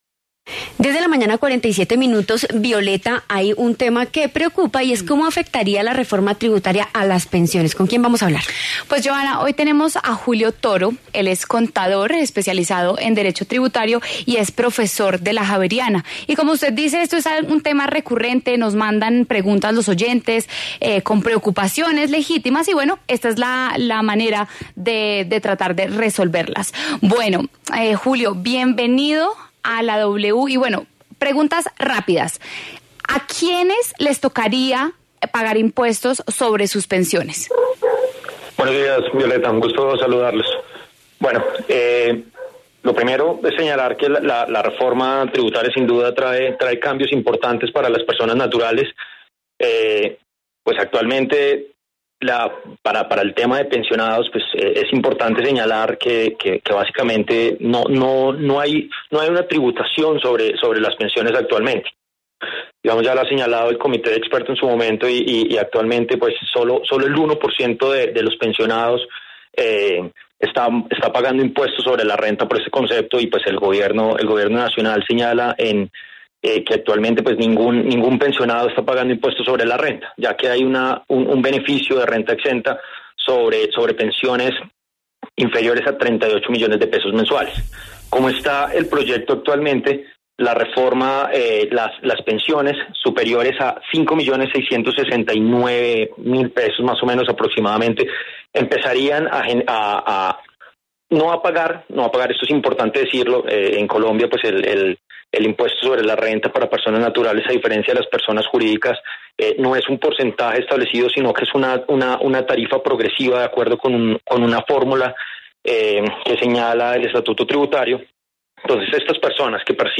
contador y profesor universitario, explicó en La W cómo impactará la reforma tributaria sobre las pensiones de los colombianos.